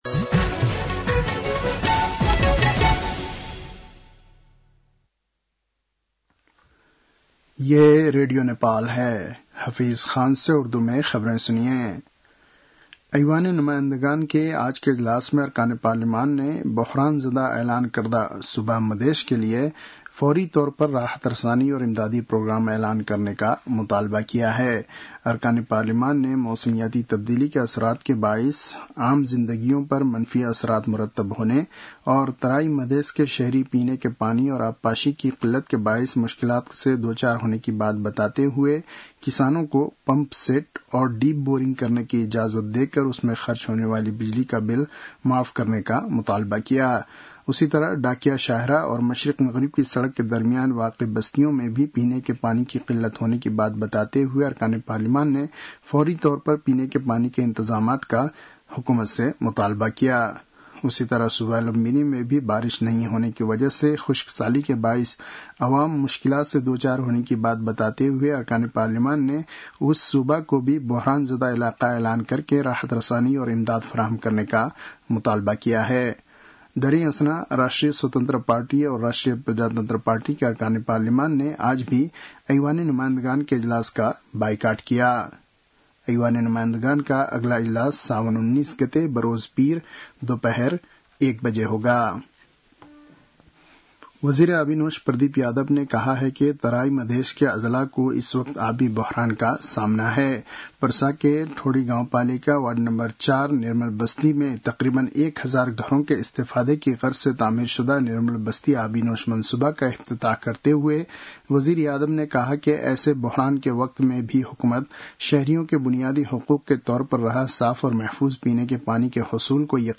उर्दु भाषामा समाचार : ९ साउन , २०८२
Urdu-news-4-09.mp3